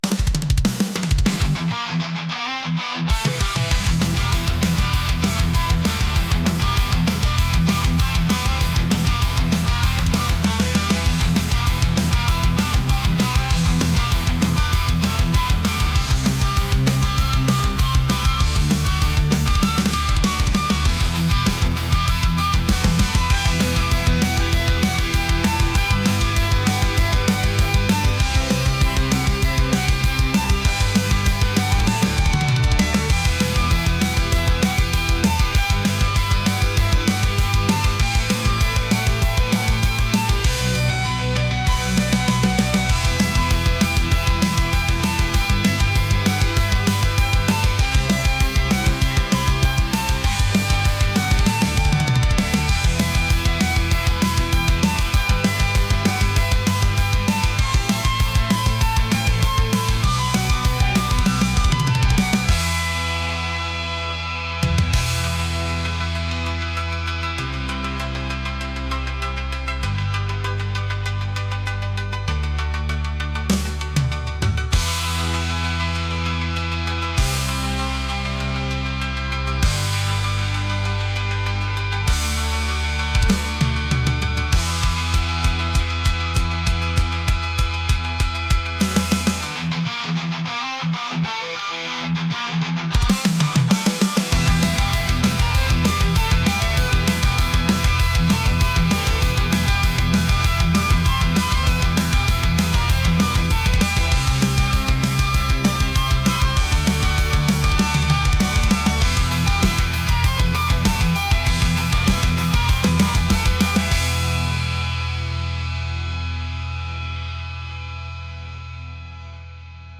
metal | aggressive | intense